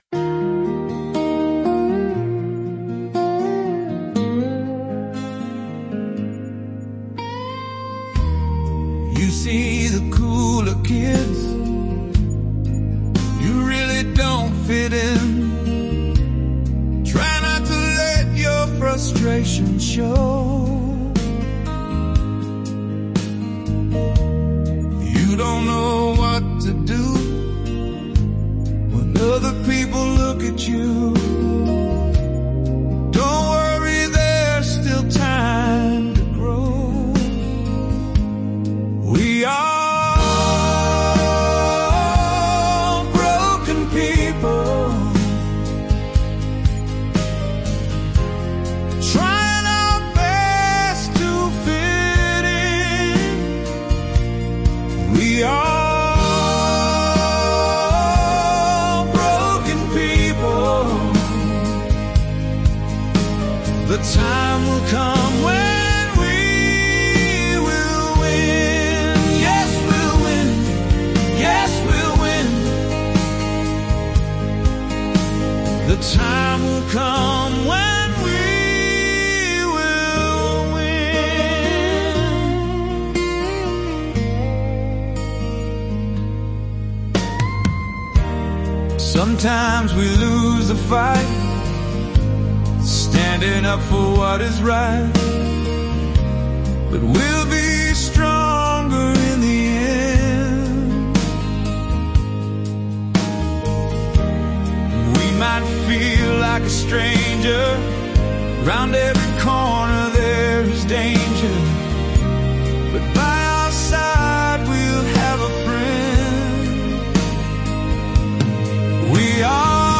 This piece is catchy and very relevant to many different situations and I know so many people will find a meaning that suits their need.